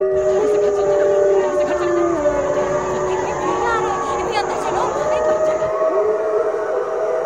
wolves howl with frightened voices.ogg
Original creative-commons licensed sounds for DJ's and music producers, recorded with high quality studio microphones.
[wolves-howl-sound-effect]-[frightened-voices-sound-effect]_ua6.mp3